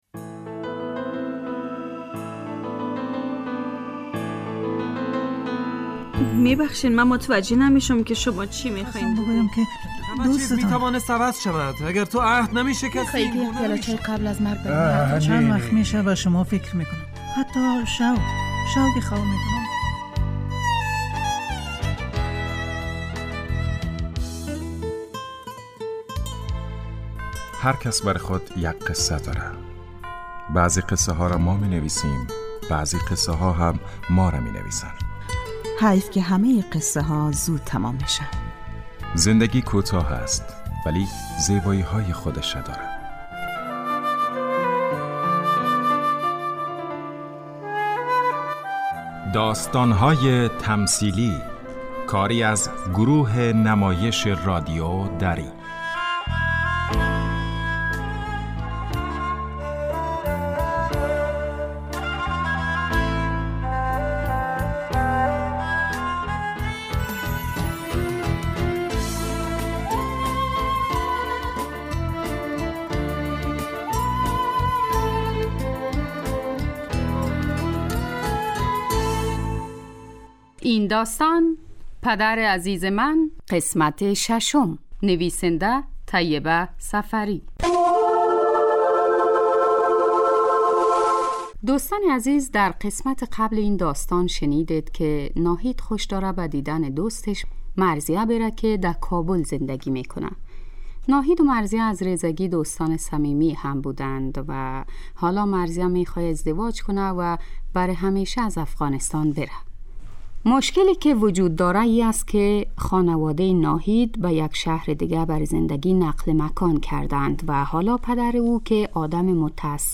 داستان تمثیلی/ پدر عزیز من